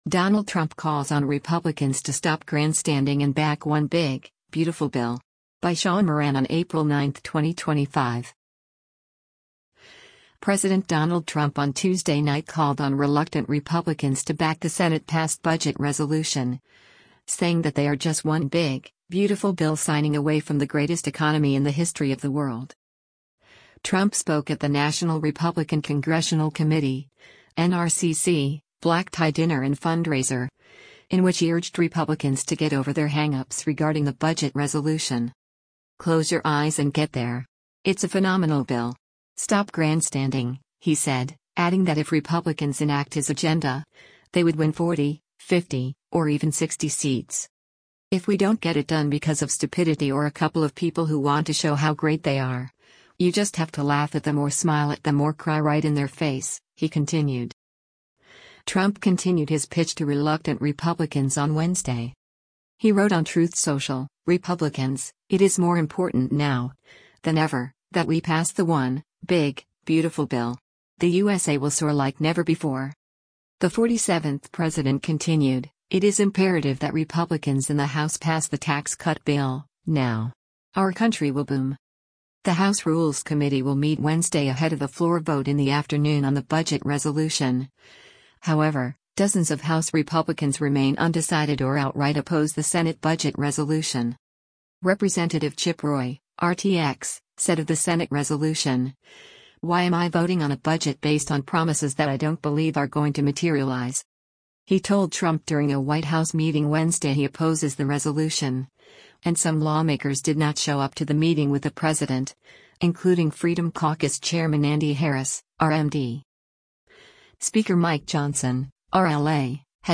U.S. President Donald Trump speaks during the National Republican Congressional Committee
Trump spoke at the National Republican Congressional Committee (NRCC) black tie dinner and fundraiser, in which he urged Republicans to get over their hang-ups regarding the budget resolution.